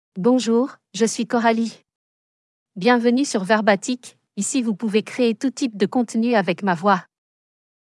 FemaleFrench (France)
CoralieFemale French AI voice
Coralie is a female AI voice for French (France).
Voice sample
Listen to Coralie's female French voice.
Coralie delivers clear pronunciation with authentic France French intonation, making your content sound professionally produced.